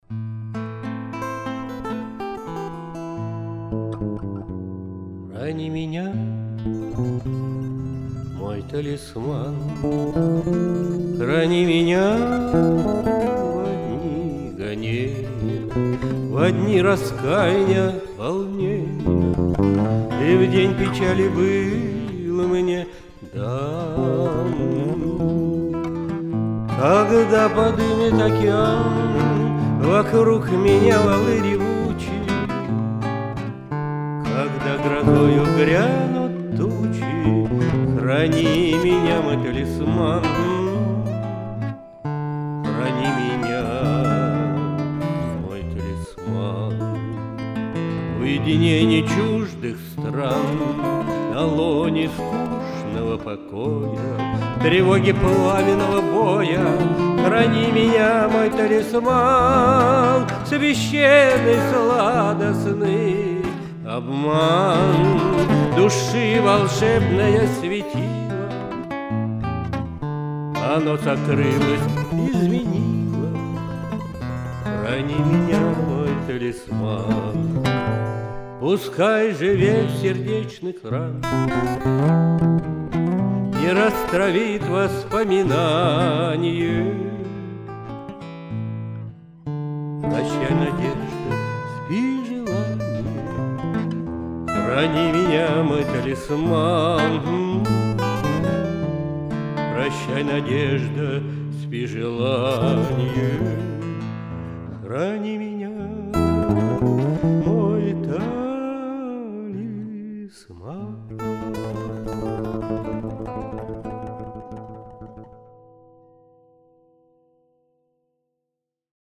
вокал, гитара
Записано в студии